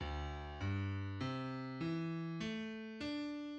{\clef bass \time 6/4 d, g, c e a d'}\addlyrics {D G c e a \markup \concat {d \super 1}}
Strój violi da gamba